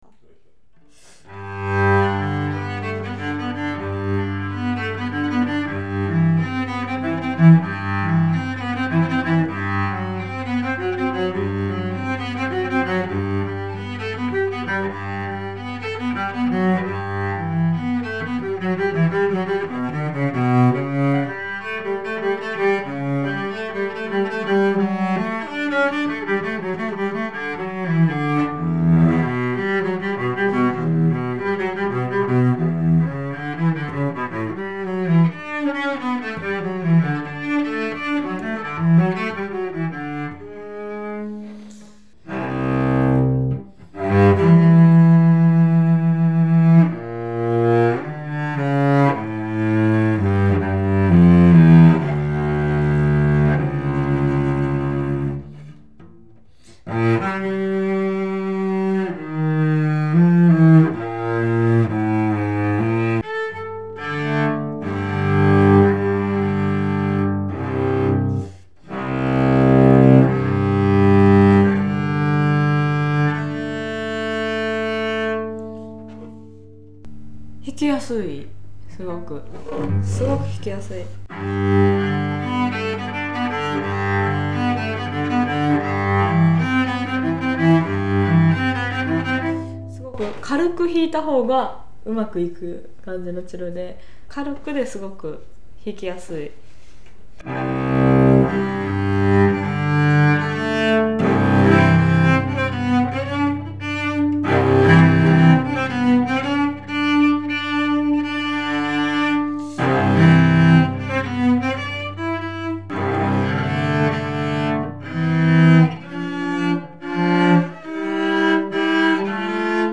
演奏者紹介)さんをお迎えし、試奏と以下のそれぞれのチェロの講評をして頂きました。
仕事も大変丁寧でどのクラスも美しく仕上がっており非常に良く鳴ります。